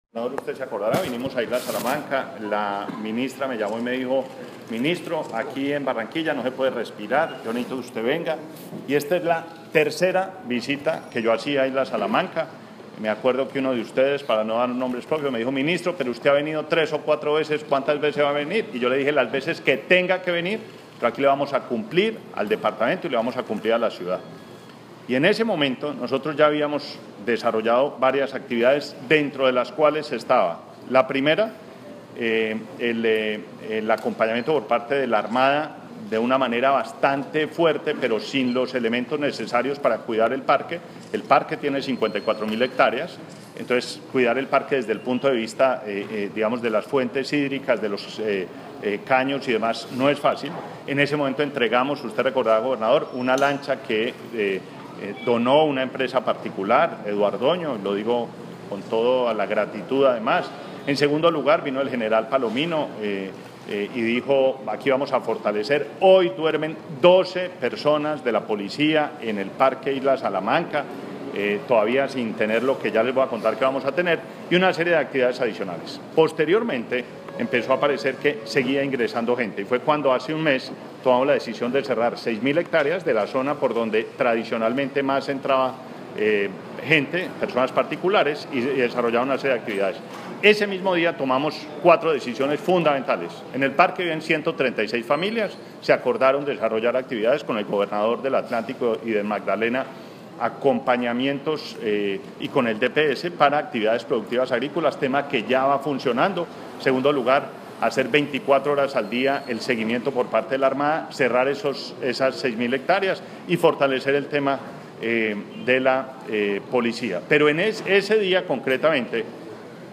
Declaraciones del Ministro de Ambiente, Gabriel Vallejo López
VOZ_MINISTRO_ISLA_SALAMNCA.mp3